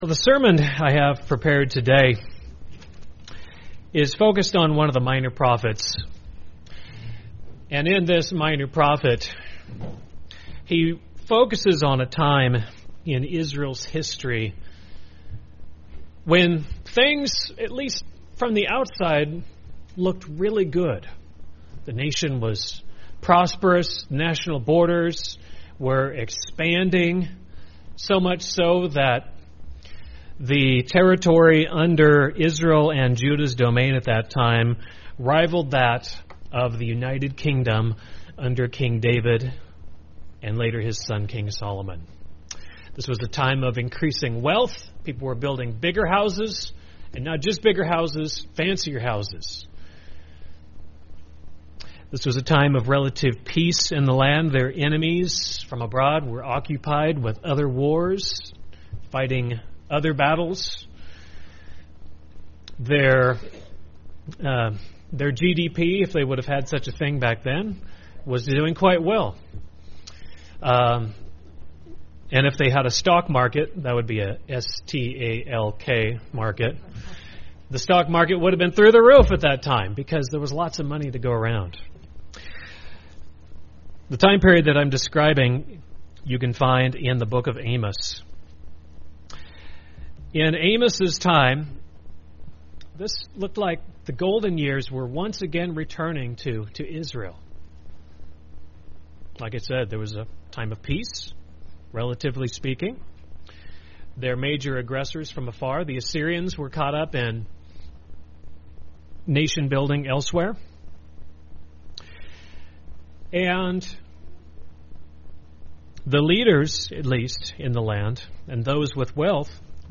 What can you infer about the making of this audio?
Given in Medford, OR